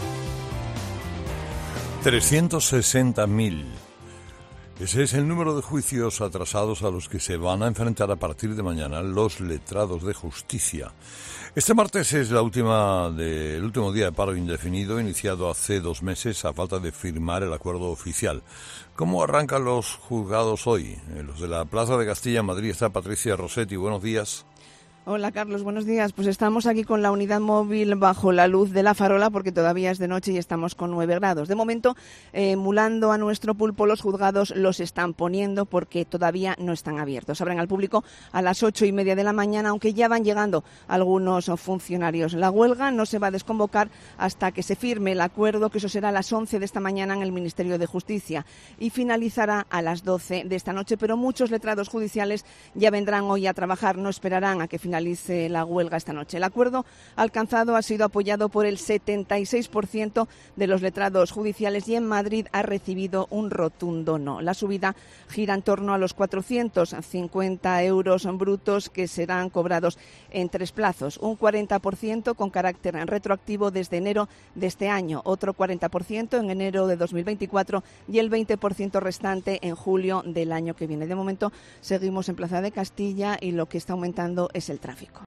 La Cadena COPE se desplaza hasta los juzgados de la Plaza de Castilla en Madrid para conocer cómo arrancan su actividad tras dos meses de huelga de los letrados de Justicia